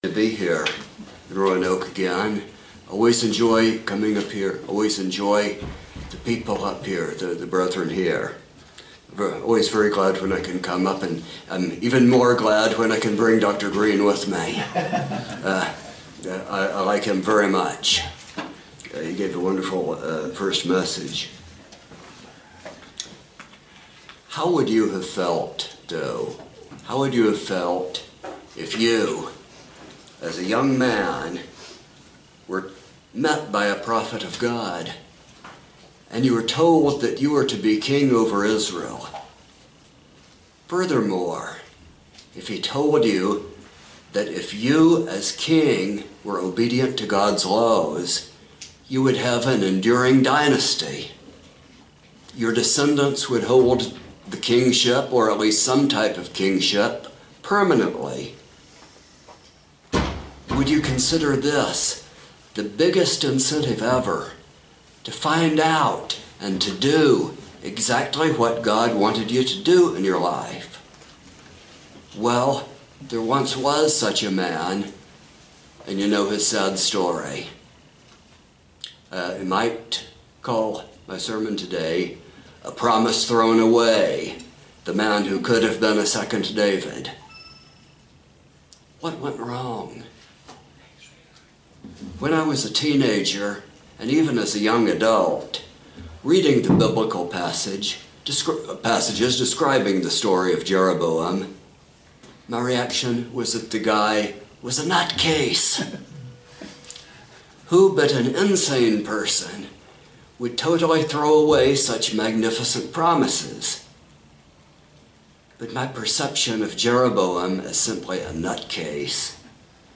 This sermon examines the progression of Jeroboam’s sins and how we must strive to avoid such tendencies in our own lives.